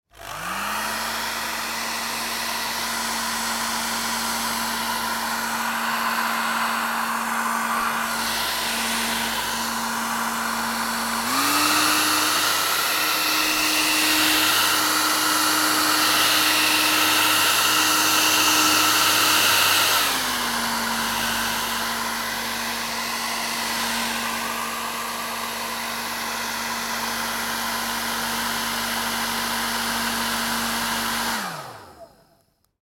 Braun International 1200 hair dryer